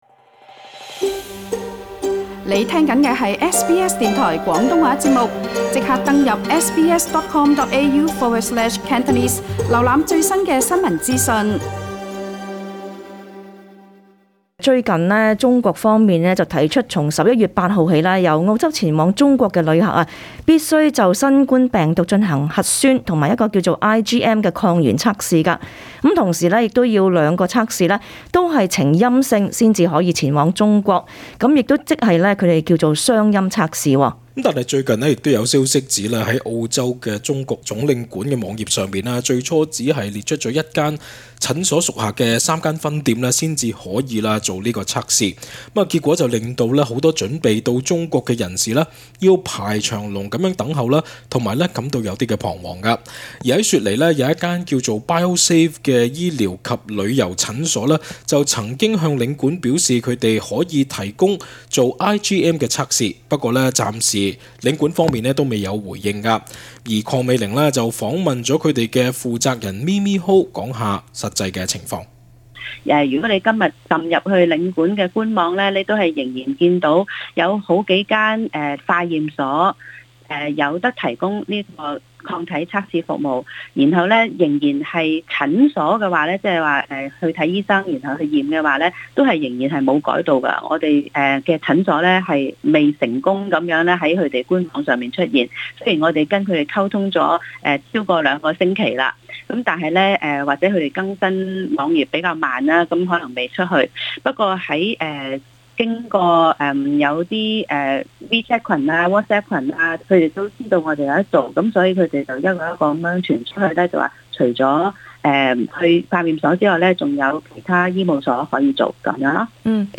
本節訪問內容只代表訪問嘉賓意見，並不代表本台立場 READ MORE 輝瑞：研發疫苗第三期測試效用逾90% 澳洲專家：未能太樂觀 為何污水有助對抗新冠疫情？